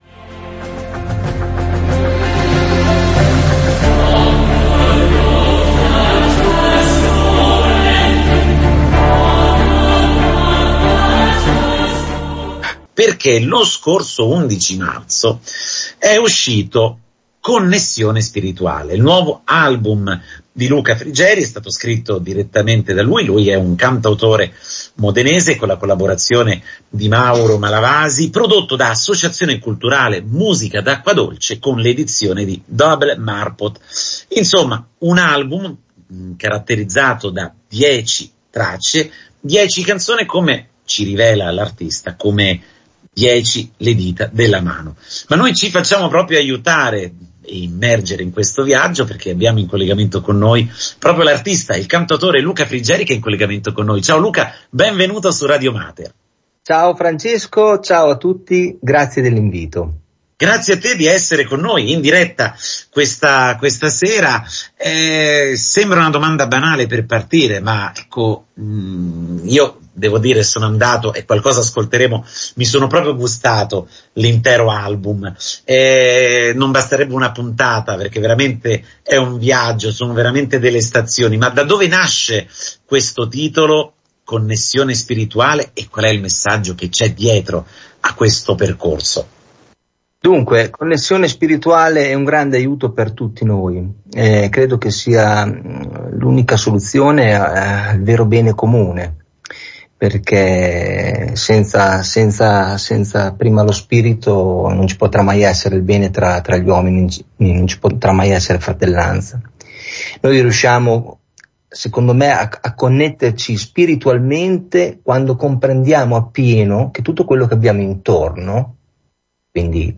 Il cantautore